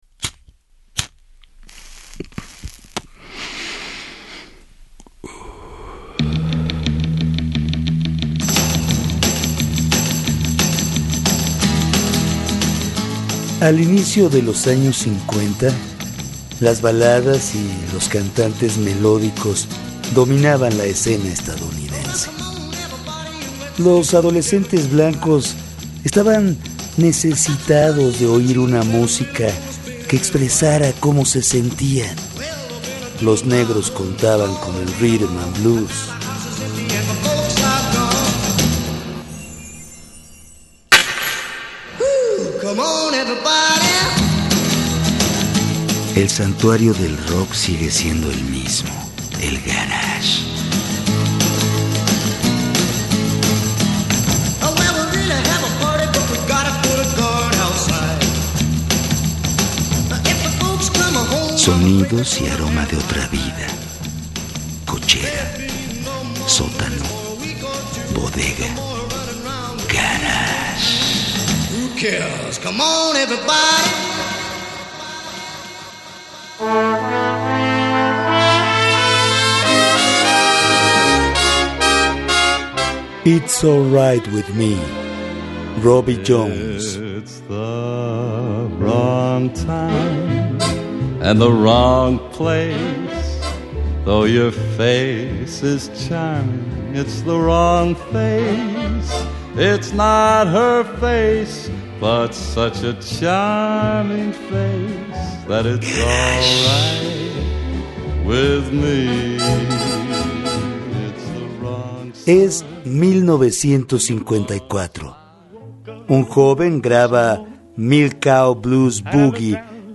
Escucha un nuevo capítulo de Las Llaves del Garage todos los lunes a las 23:30 horas en el 1060 de AM, Radio Educación , Ciudad de México.